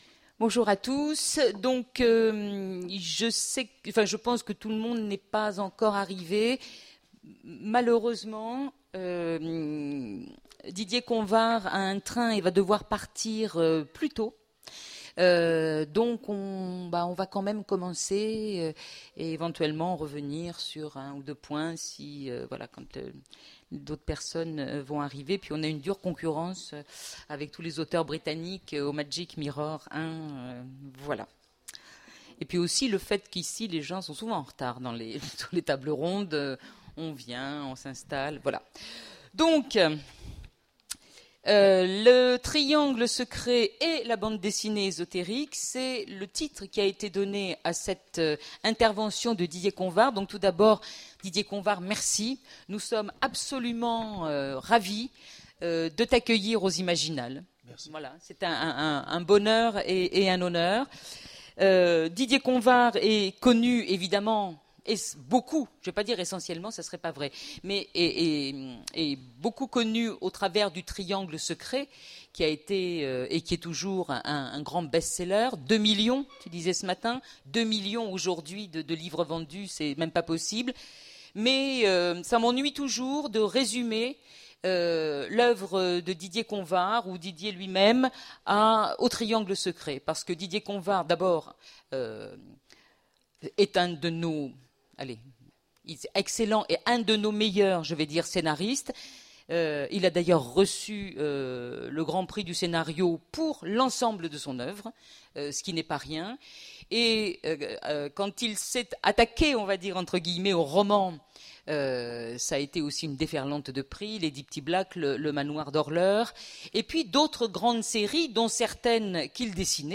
Imaginales 2015 : Conférence Le triangle secret...